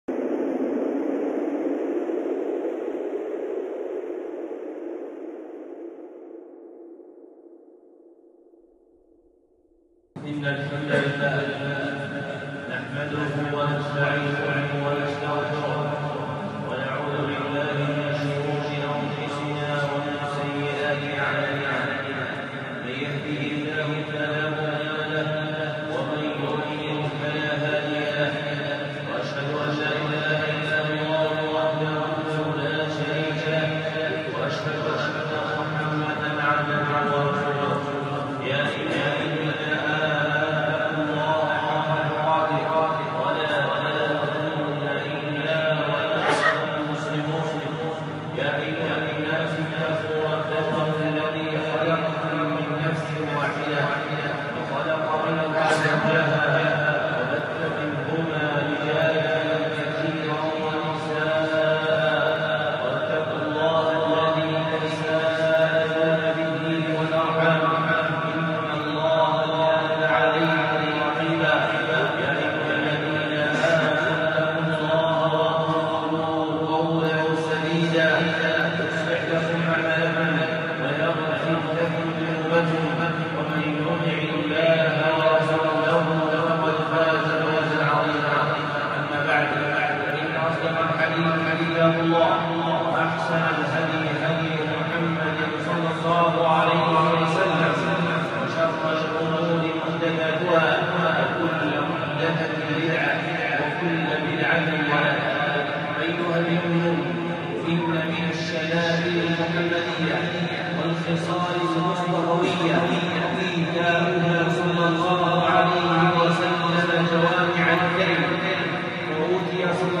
خطبة (ميزان الأعمال)